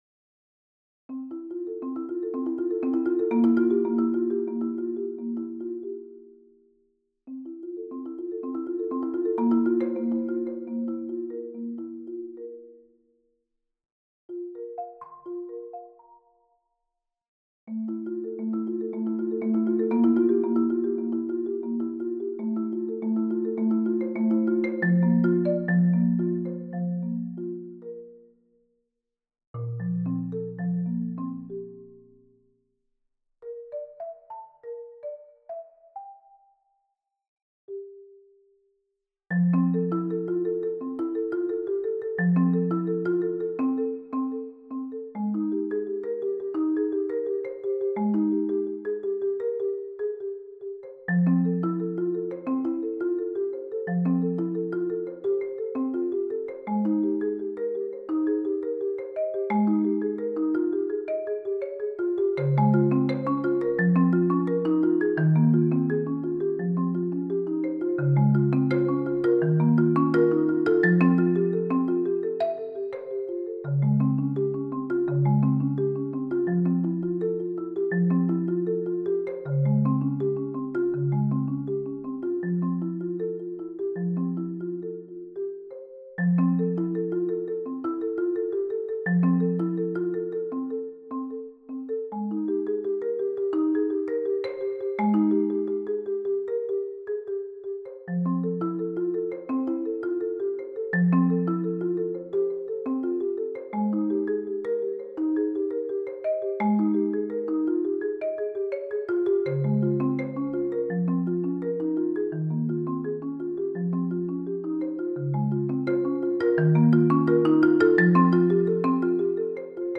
Voicing: Marimba Solo